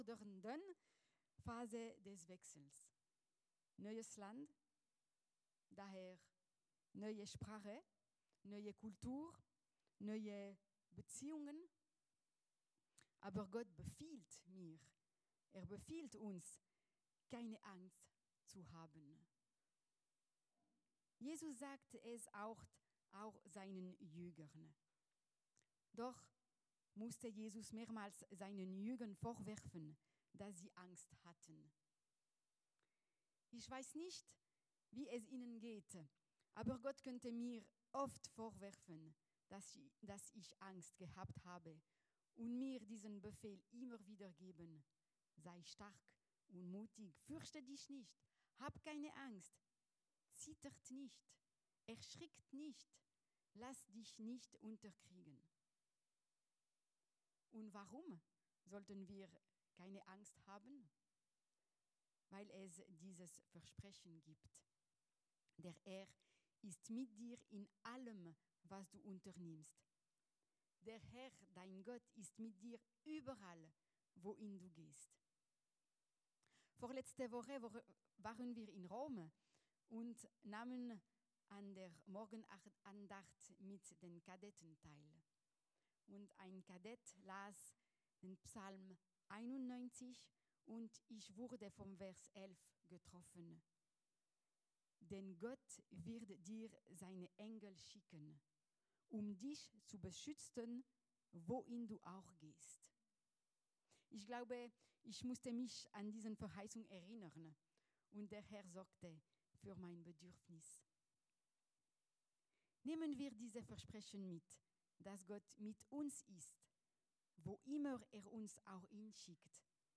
Predigten Heilsarmee Aargau Süd – Kann Jesus noch etwas Heute Predigten der Heilsarmee Aargau Süd Home Predigten Aktuelle Seite: Startseite Predigten Kann Jesus noch etwas Heute ↑↑↑ Dienstag, 07.